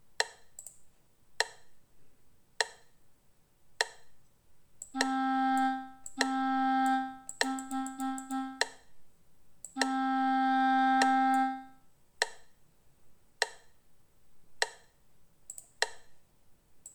A continuación escoitaredes unha serie de códigos morse coma os que acabamos de aprender, e deberemos escoller de entre as diferentes opcións que se presentan a imaxe que máis se axusta a cada código.